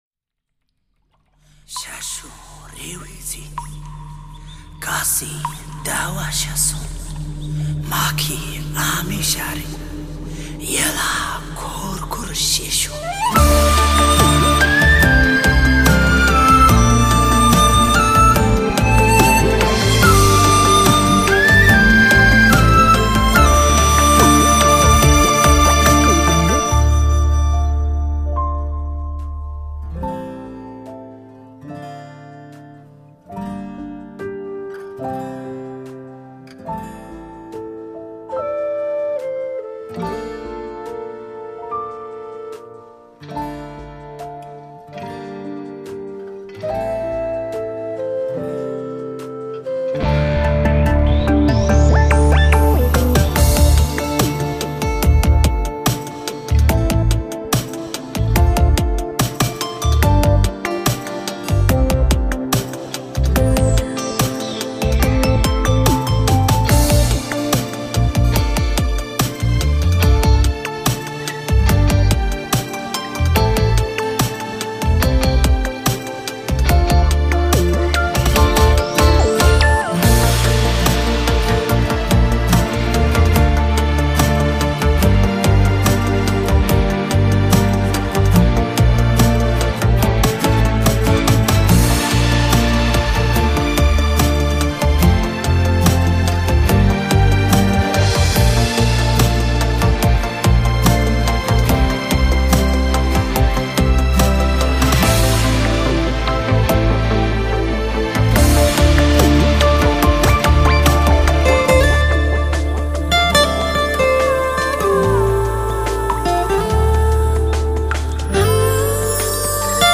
无 调式 : F 曲类